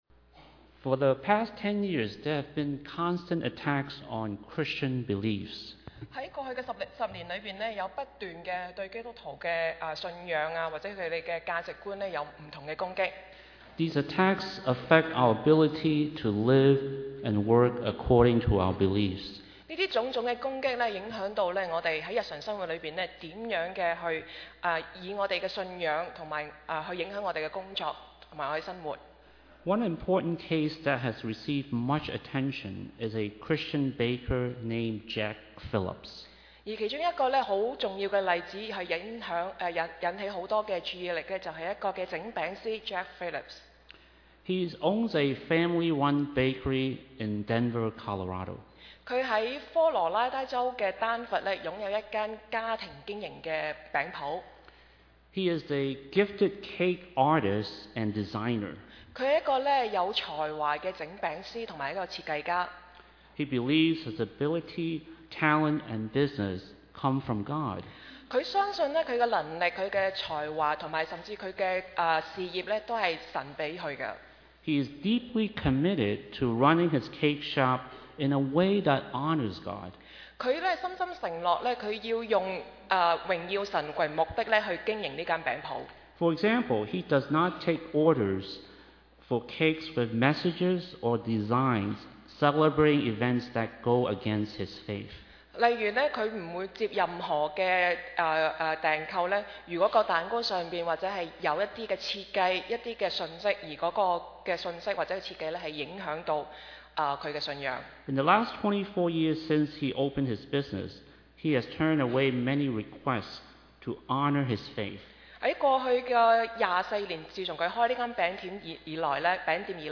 二零一八年二月份講道 – February 2018